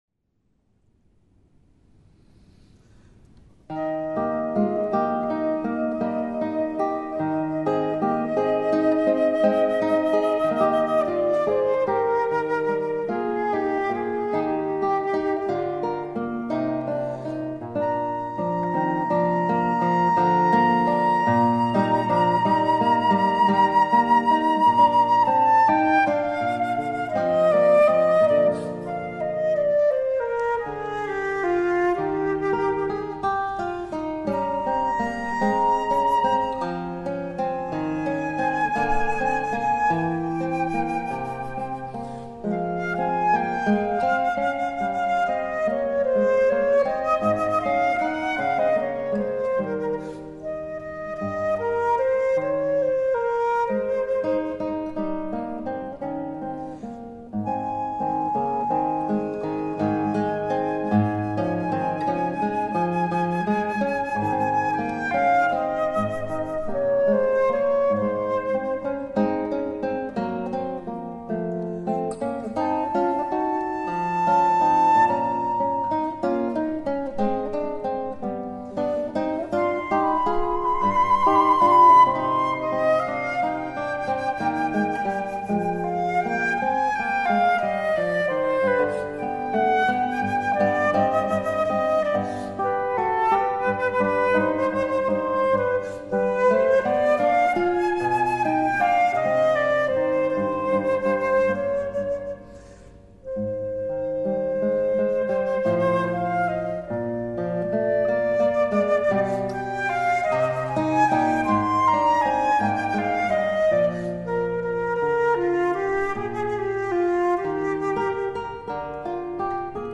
Per flauto e chitarra